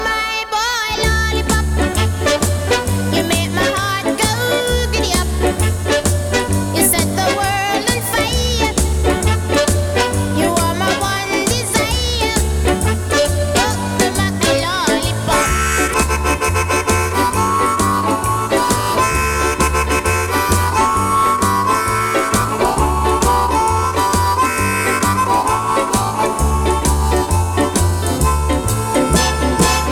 # Ska